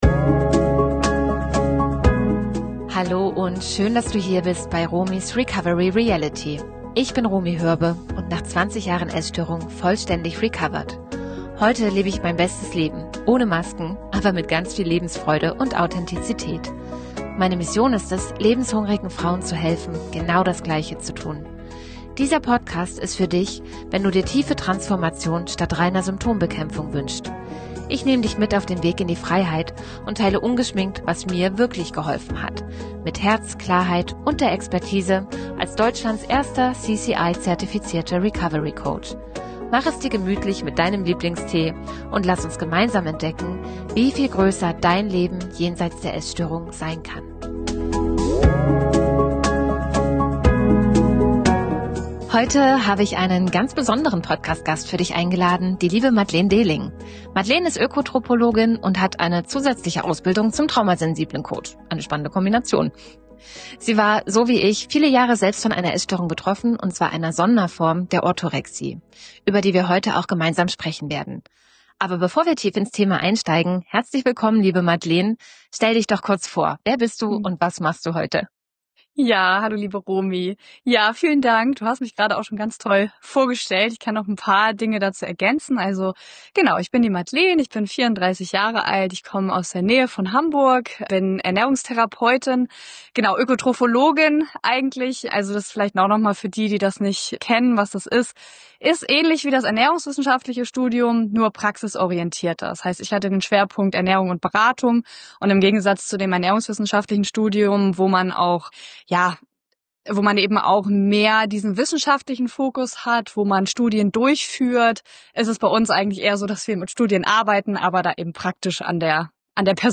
Heute spreche ich mit einem ganz besonderen Gast über ein Thema, das oft übersehen wird: Orthorexie – die zwanghafte Beschäftigung mit gesunder Ernährung.